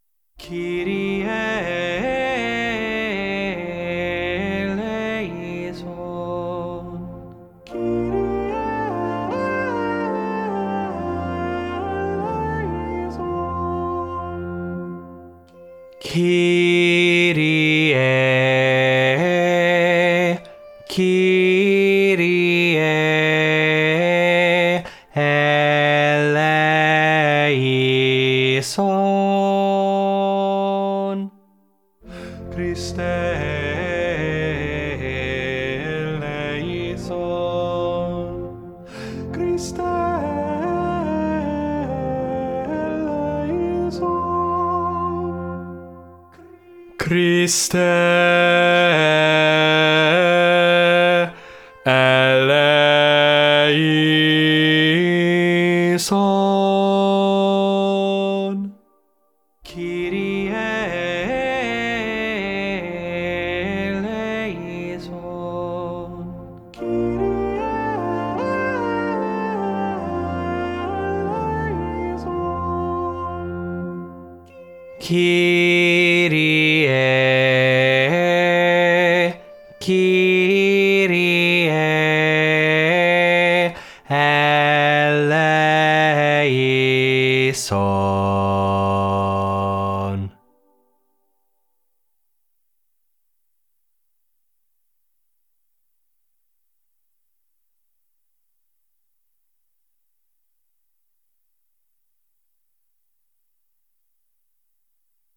4645 BASS Durieux Kyrie